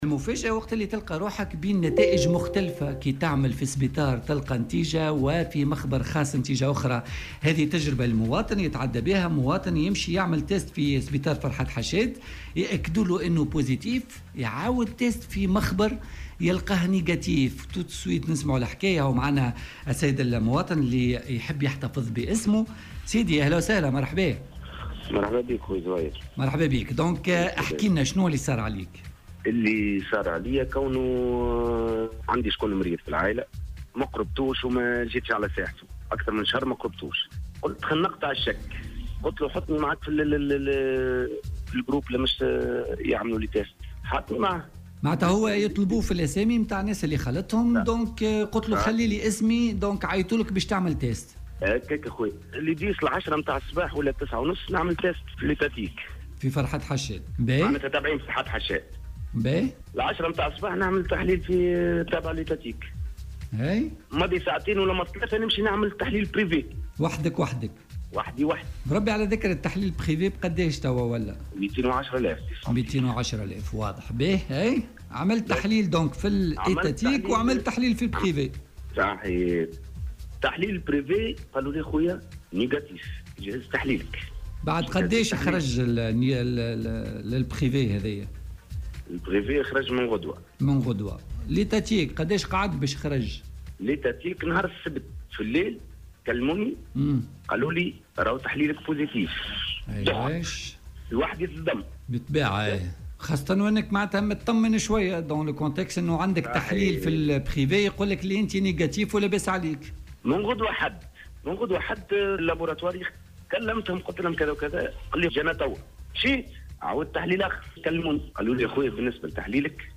وأوضح في مداخلة له اليوم على "الجوهرة أف أم" أنه قام بإجراء التحليل الأول بمستشفى فرحات حشاد وذلك بصفته من المخالطين لحالة تأكدت إصابتها بالفيروس وورد التحليل إيجابيا، لكنه قام أيضا بتحليل آخر في مخبر مرخّص له من قبل وزارة الصحة وصدرت نتيجته سلبية قبل صدور نتيجة التحليل الأول.